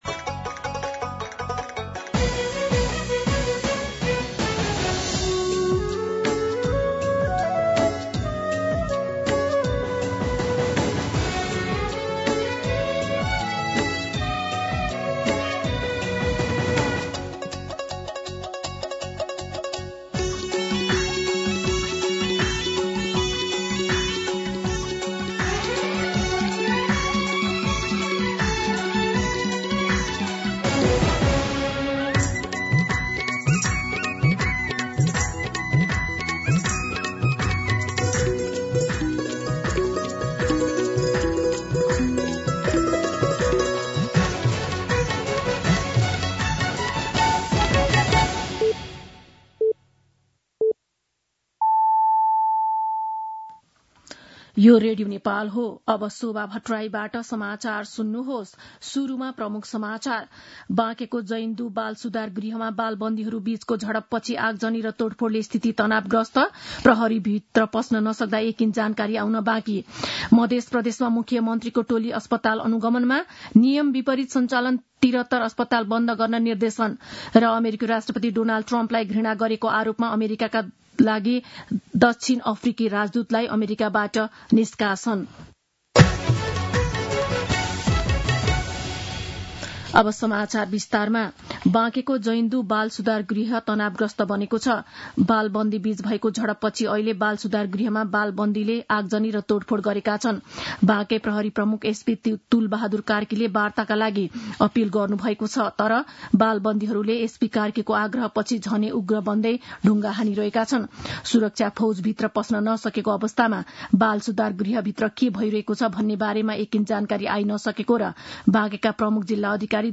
दिउँसो ३ बजेको नेपाली समाचार : २ चैत , २०८१
3-pm-Nepali-News-1.mp3